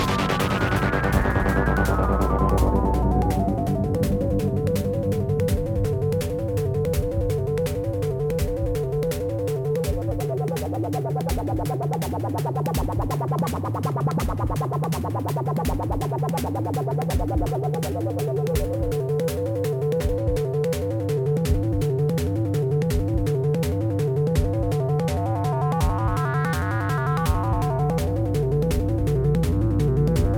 Ens.voc & instr.